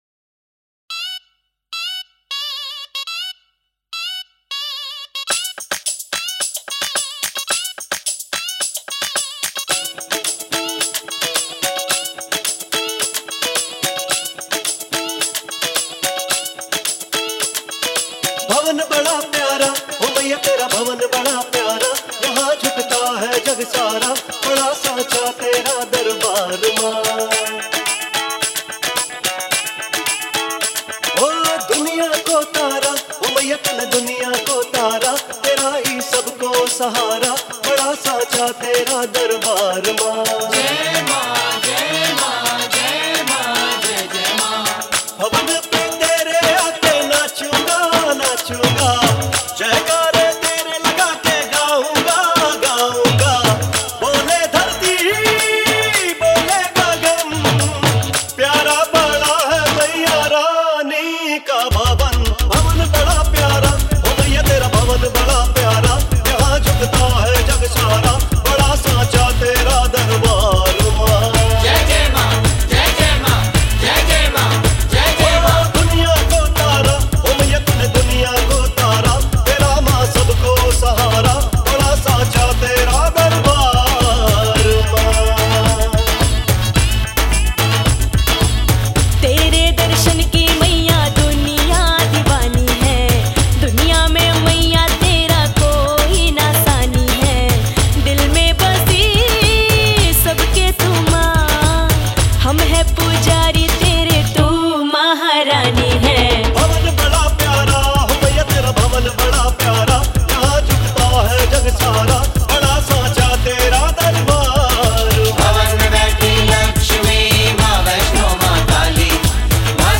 bhajan album